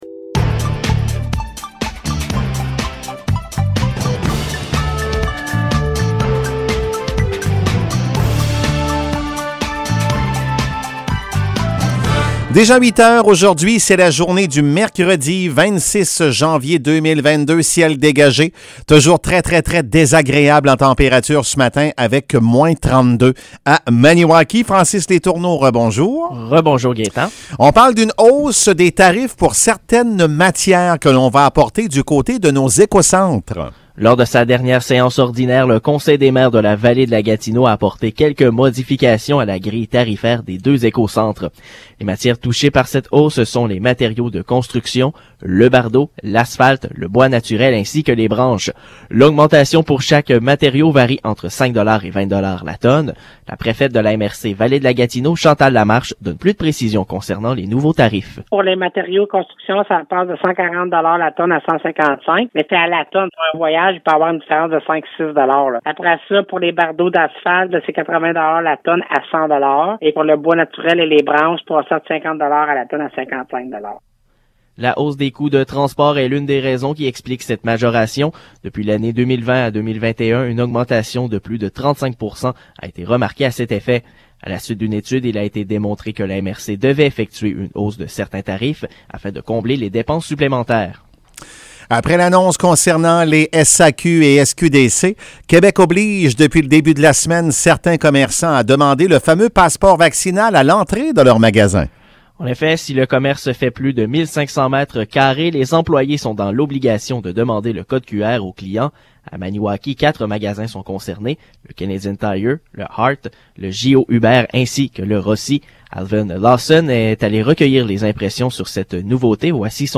Nouvelles locales - 26 janvier 2022 - 8 h